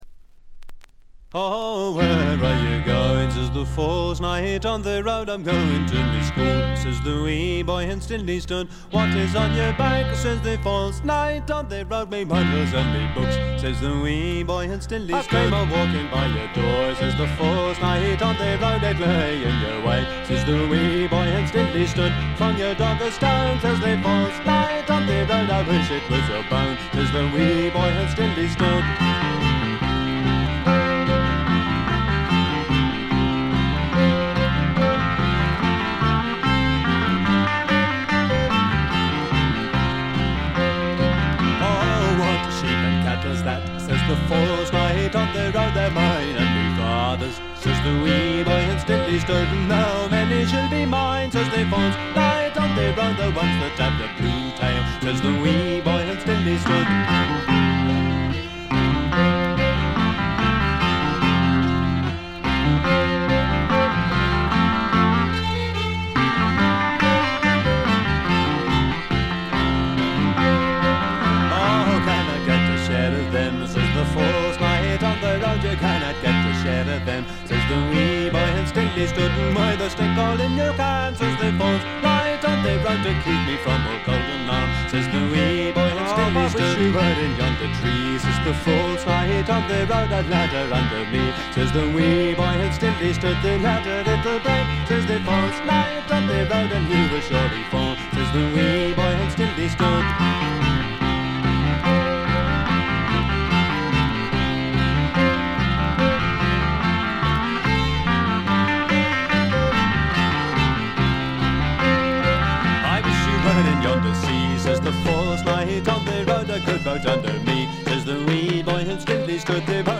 これ以外は静音部で軽微なチリプチ、バックグラウンドノイズ。
英国エレクトリック・フォーク最高峰の一枚。
試聴曲は現品からの取り込み音源です。